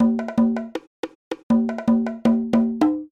描述：康加棕榈封闭样品
标签： 关闭 康加 棕榈 样品
声道立体声